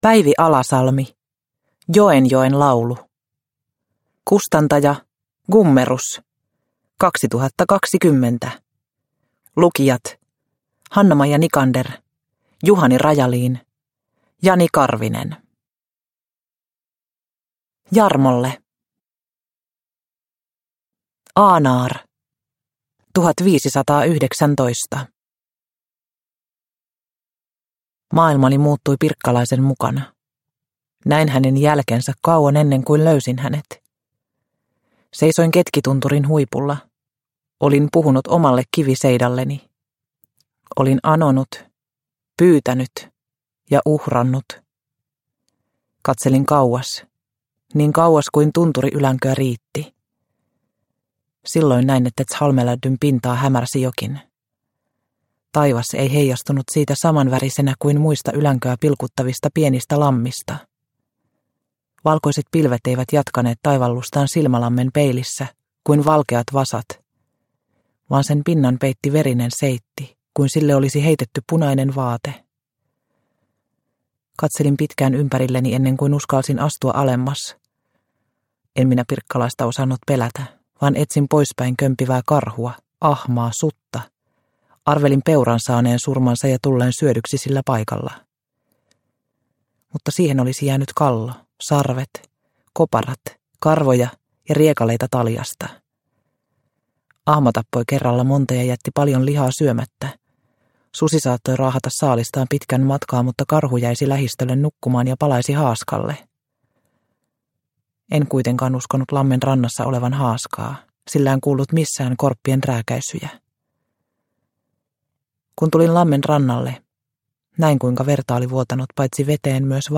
Joenjoen laulu – Ljudbok – Laddas ner